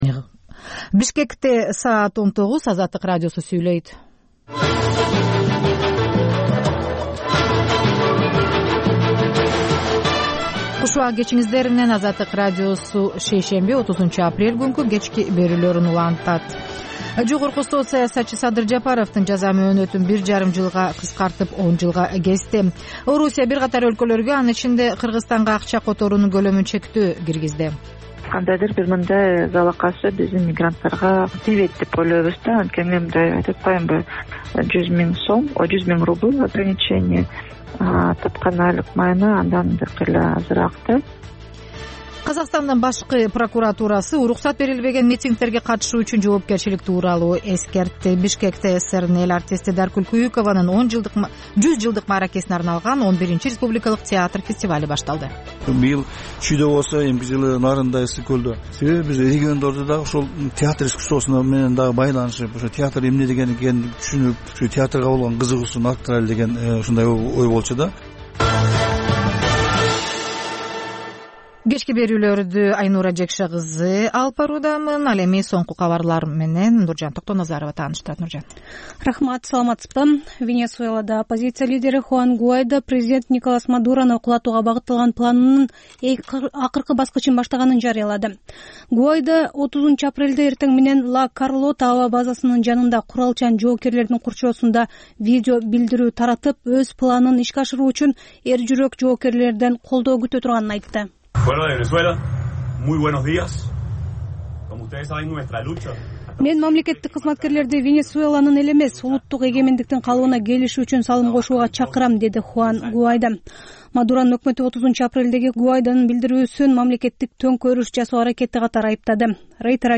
Бул үналгы берүү ар күнү Бишкек убакыты боюнча саат 19:00дөн 20:00гө чейин обого түз чыгат.